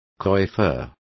Complete with pronunciation of the translation of coiffeur.